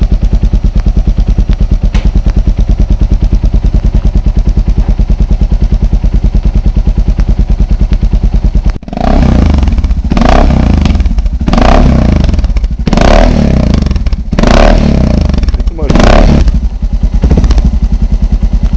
Sound Suzuki DR 650, Einzeltopf
Komplette EGU Auspuffanlage Suzuki DR 650, Einzeltopf [256 KB]